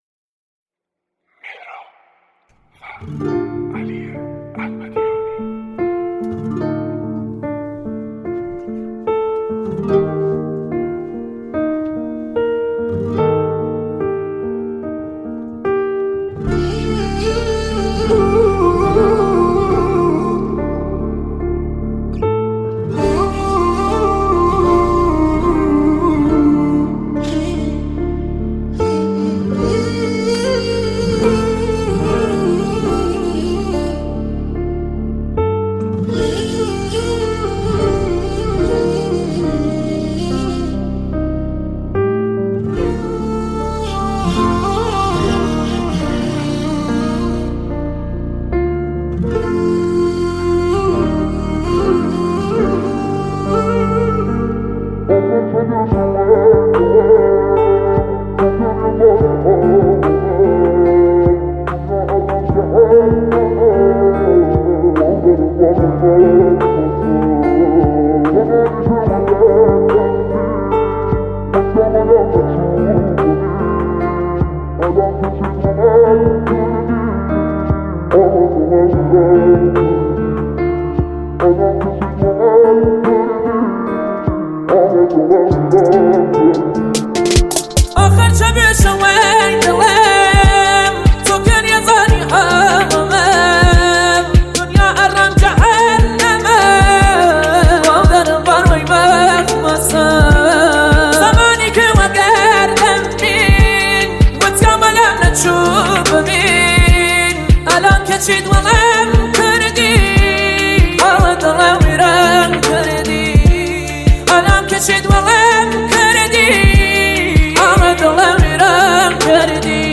غمگین و احساسی